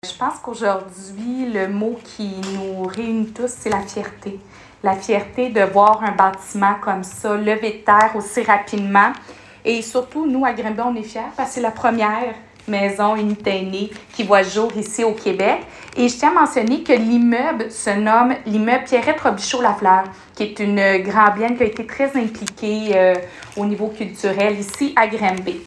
La mairesse de Granby, Julie Bourdon était très fière de recevoir les clés ce matin.